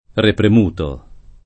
repremuto [ reprem 2 to ]